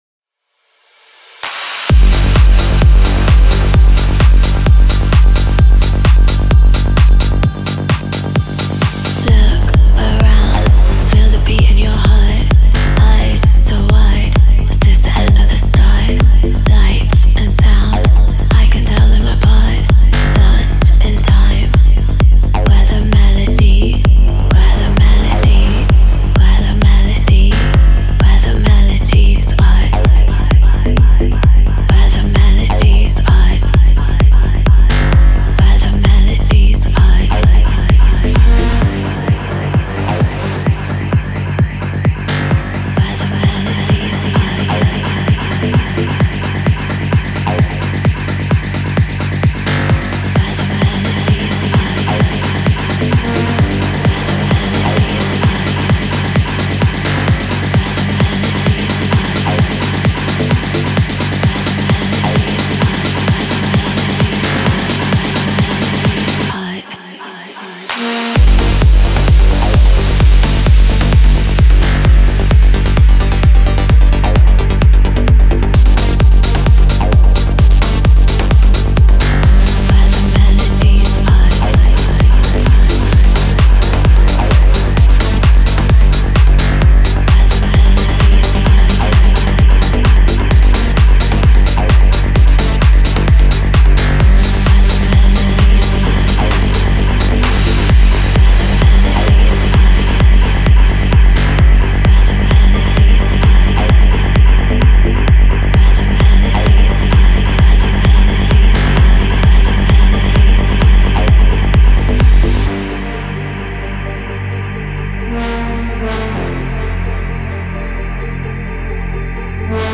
Трек размещён в разделе Зарубежная музыка / Танцевальная.